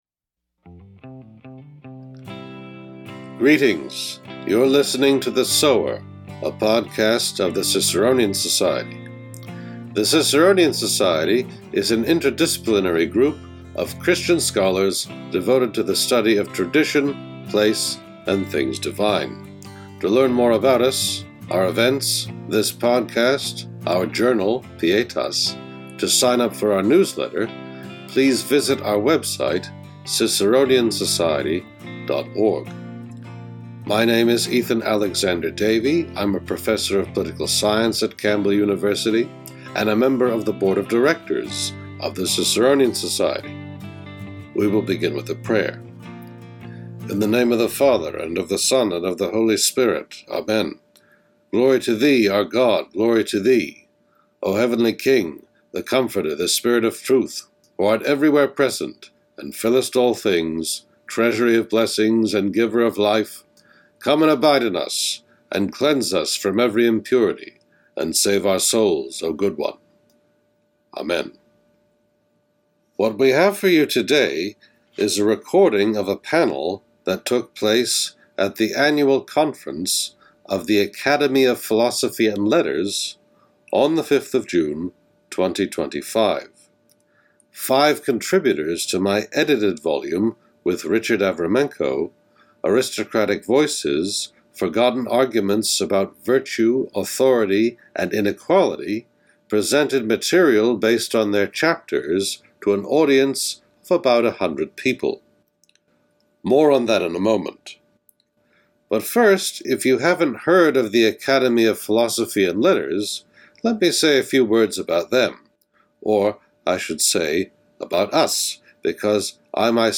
This special episode is a recording of a panel at the June 2025 meeting of the Academy of Philosophy and Letters in College Park, Maryland.